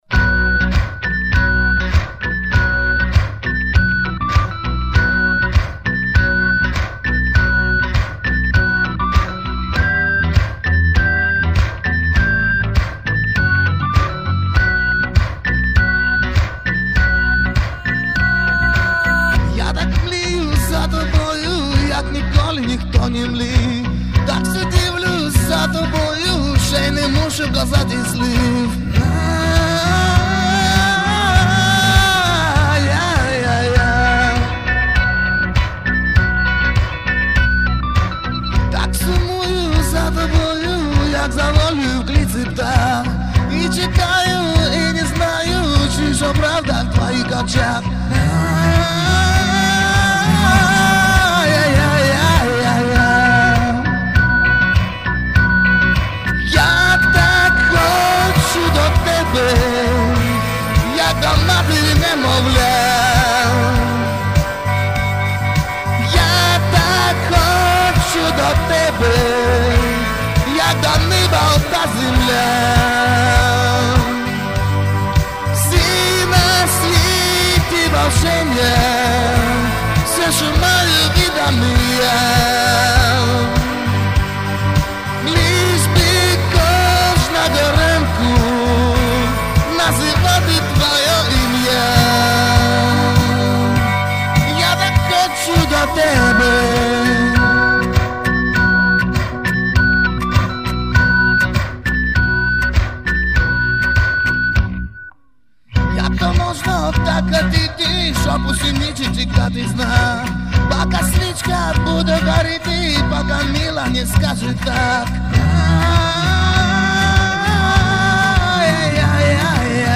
...мощно с хрипатцой
Спели очень близко к оригиналу, голос красивый...